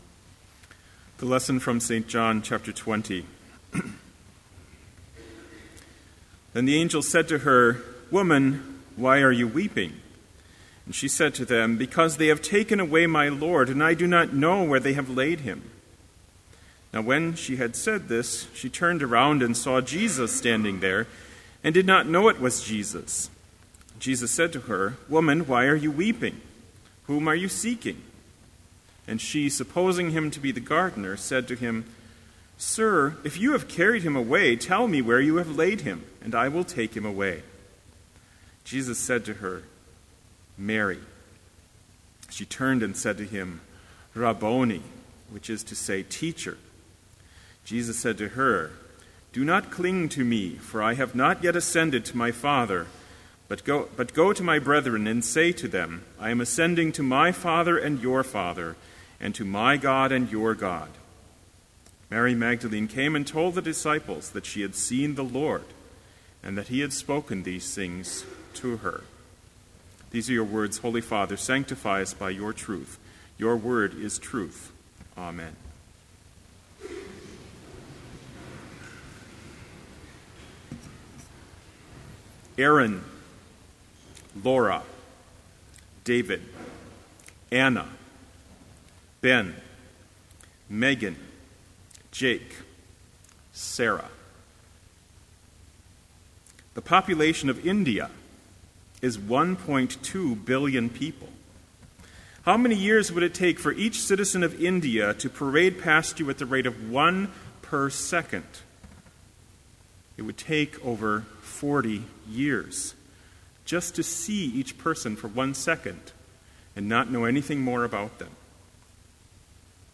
Complete Service
This Chapel Service was held in Trinity Chapel at Bethany Lutheran College on Wednesday, April 10, 2013, at 10 a.m. Page and hymn numbers are from the Evangelical Lutheran Hymnary.